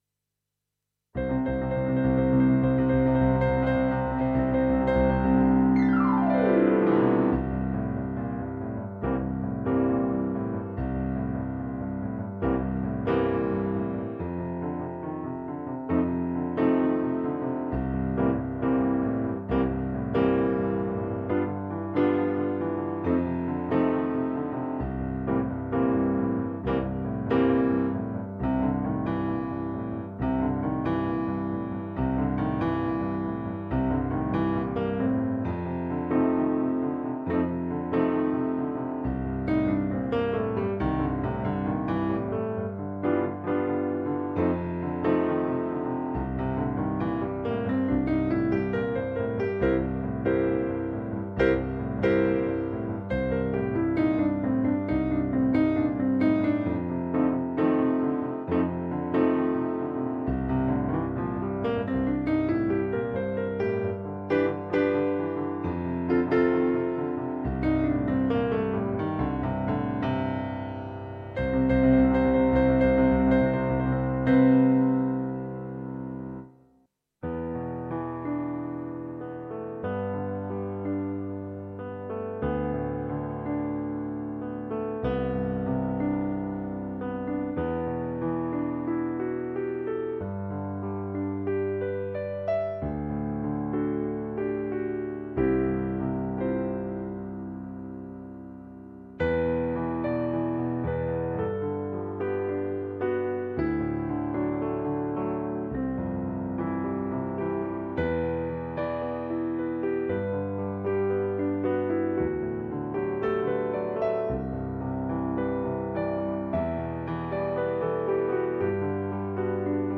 Kurzweil-Pc3-Piano-Demo.mp3